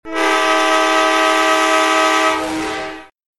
TrainHorn.wav